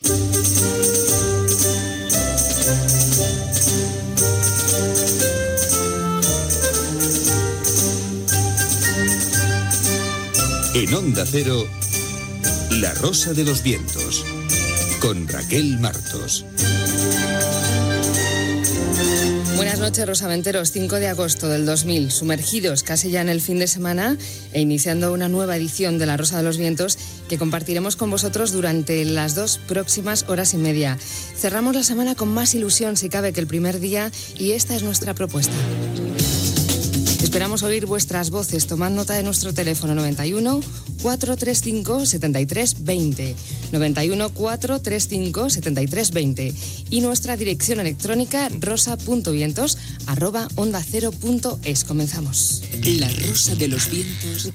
Fragment de l'inici del programa a la temporada d'estiu.
Divulgació
FM